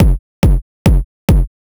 KICK021_TEKNO_140_X_SC2.wav